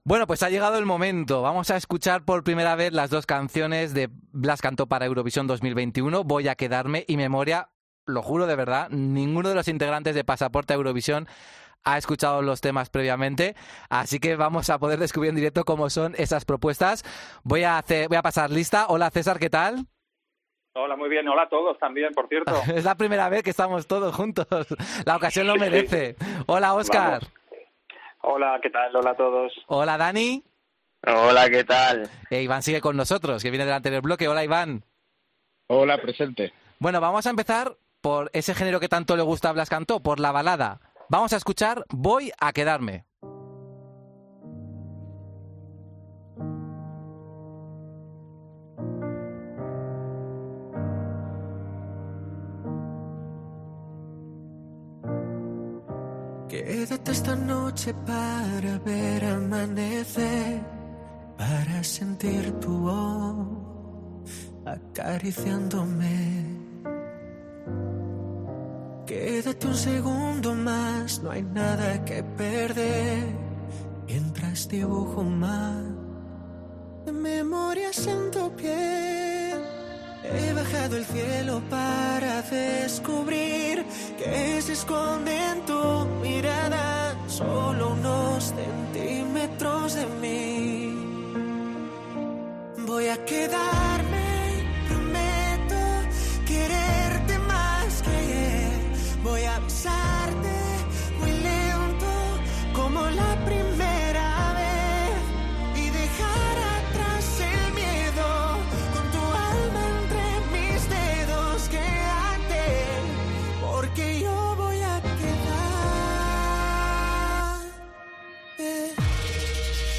En el programa "Pasaporte a Eurovisión" de COPE hemos escuchado este miércoles las canciones en el momento de su lanzamiento y los colaboradores del espacio han expresado su opinión sobre ambas.